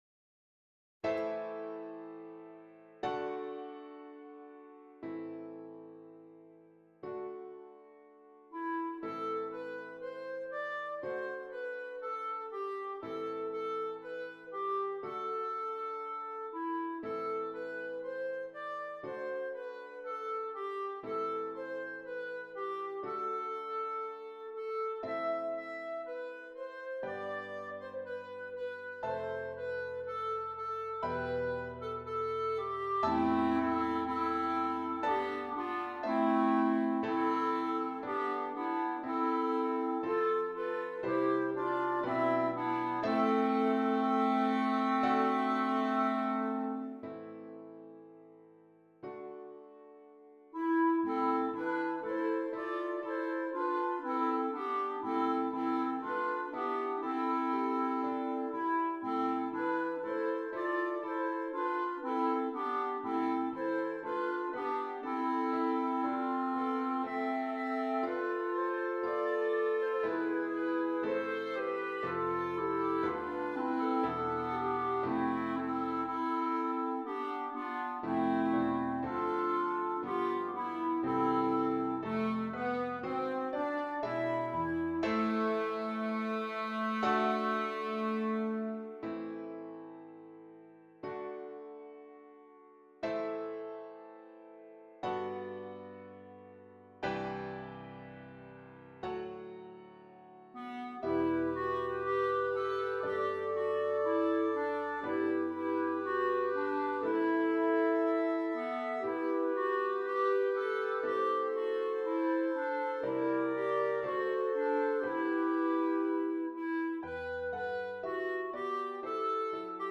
Christmas
3 Clarinets and Keyboard
Traditional